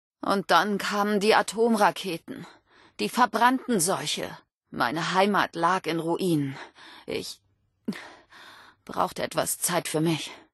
Vault-76-Aufseherin_00402a67_3.ogg (OGG-Mediendatei, Dateigröße: 81 KB.